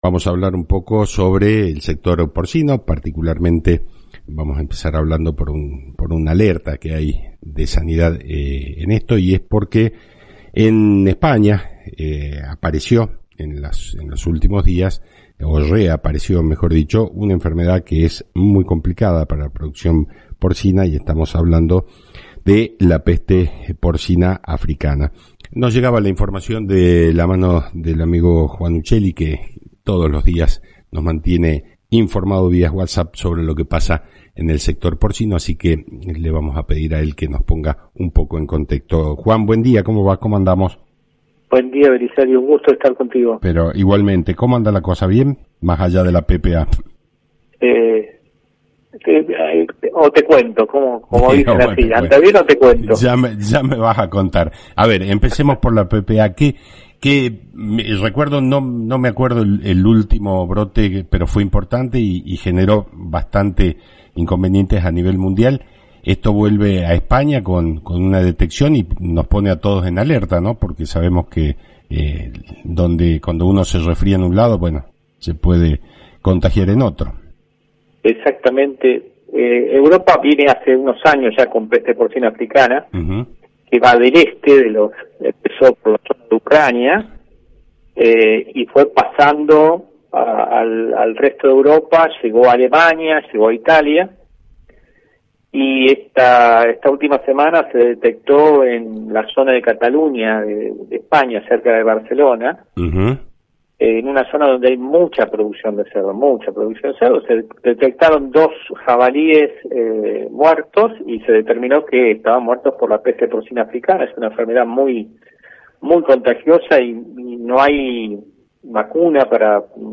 En el programa Claves del Campo (AM 840 – Radio Salta)